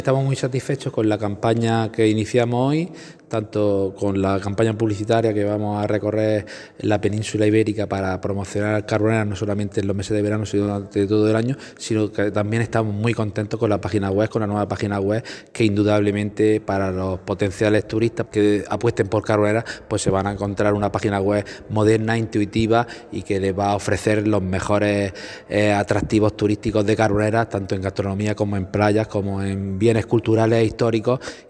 Declaraciones-alcalde.mp3